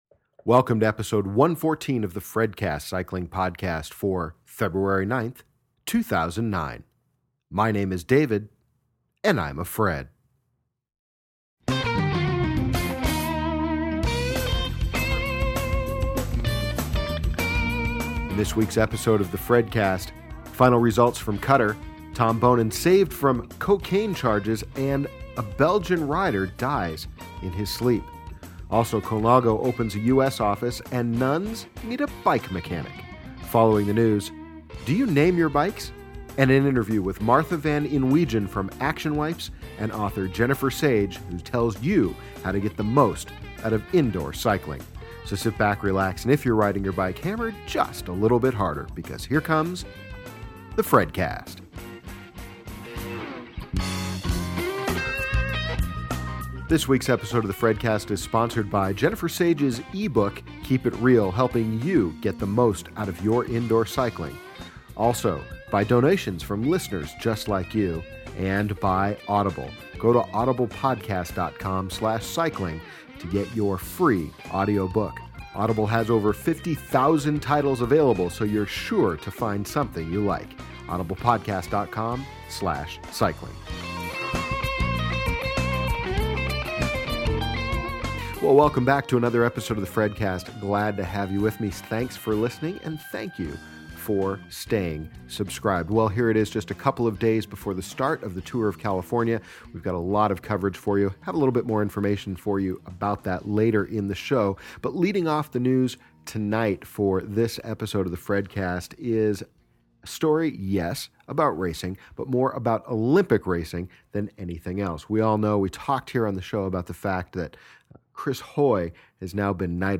In addition to the interview, there’s a full slate of news including Great Britain’s cycling secret weapon, a product recall from Mavic, Tour of Qatar, Nuns Who Need Bike Mechanics, and more.